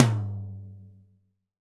TOM TOM108.wav